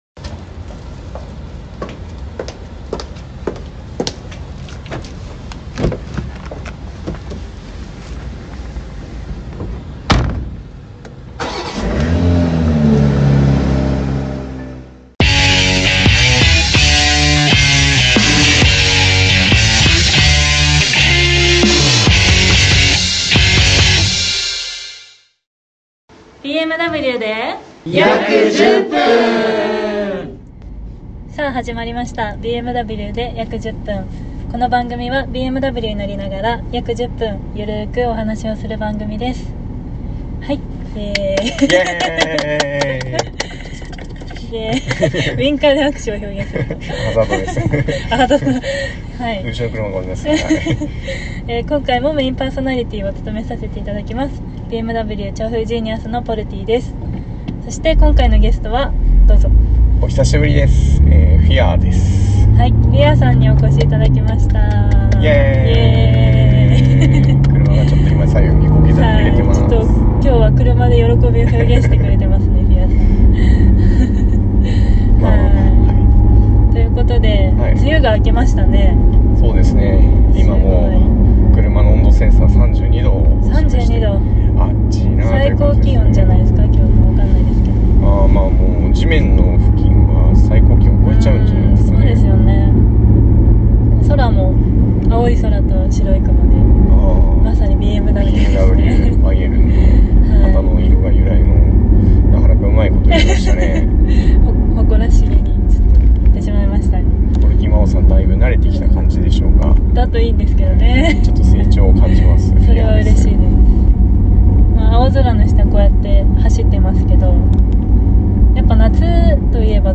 実際にＸ４Ｍに乗りながらの解説は必聴です